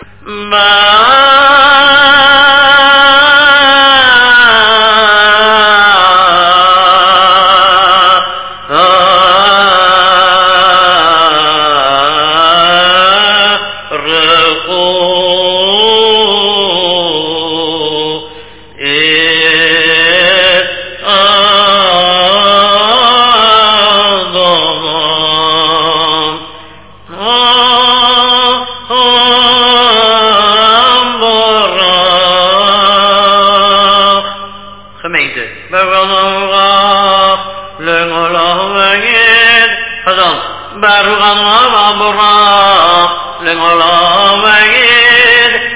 Chazzan repeats